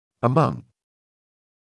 [ə’mʌŋ][э’ман]среди; в числе; среди (более чем 2-х элементов)
among.mp3